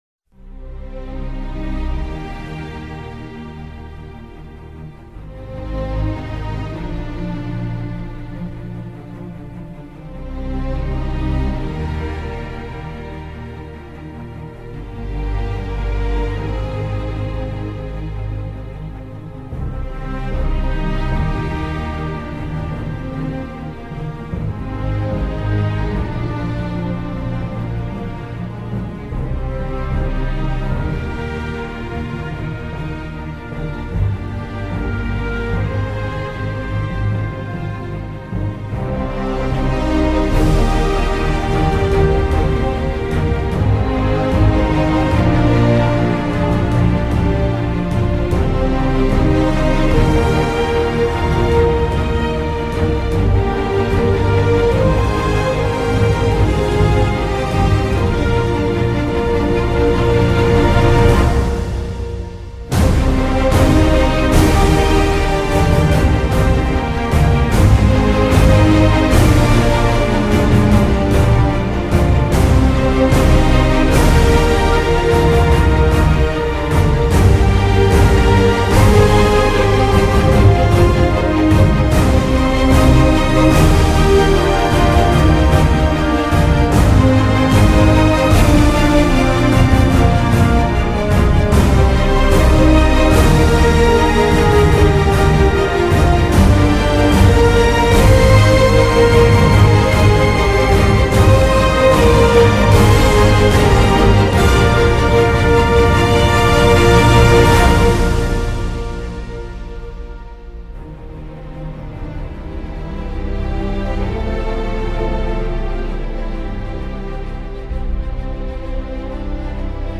Something to help you awaken yourselves to the newest of the Wheels of time. (bad recording from a stereo Mic and a small room but yay. I tried to enhance it as well as I could with magic music maker)